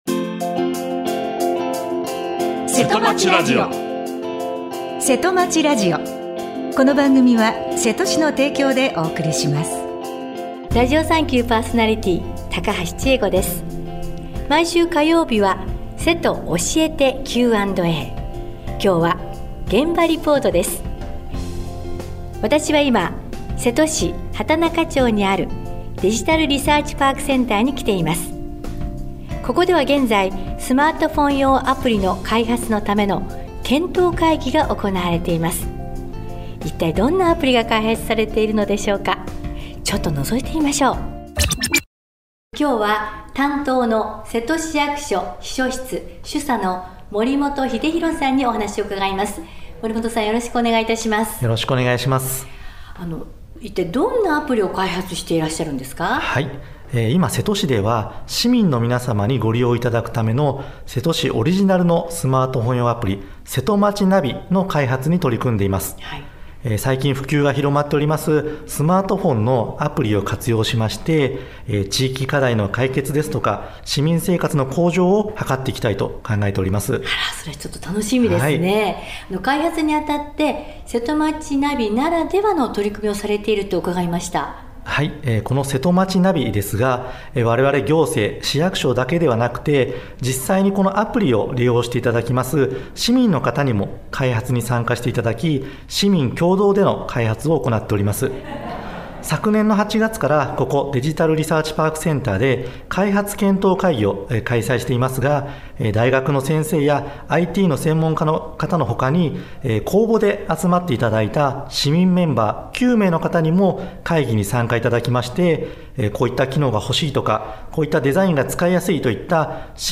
毎週火曜日は「せとおしえてQ&A」、今日は現場リポートです。